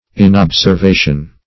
Search Result for " inobservation" : The Collaborative International Dictionary of English v.0.48: Inobservation \In*ob`ser*va"tion\, n. [Cf. F. inobservation.]